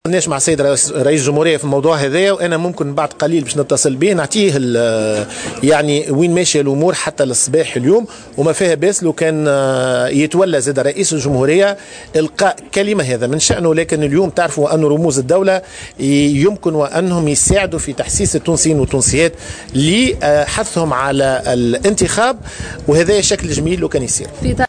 نبيل بفون رئيس الهيئة